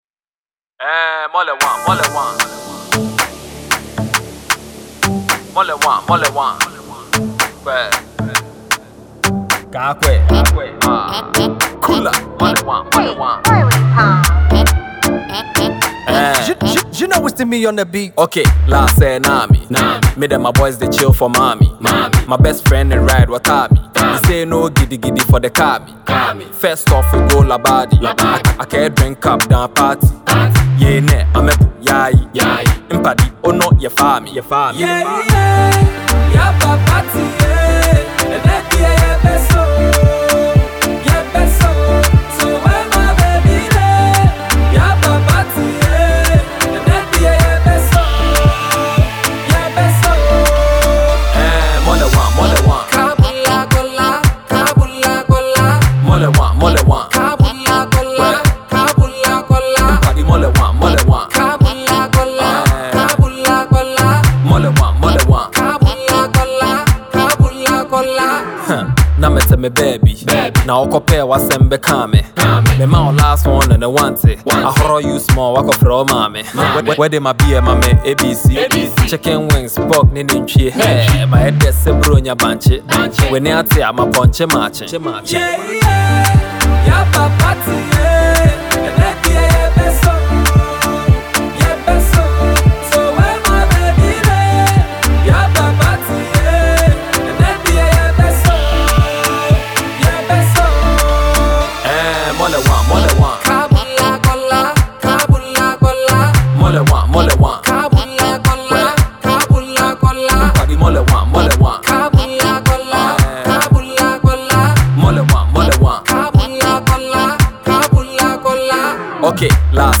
an uptempo danceable song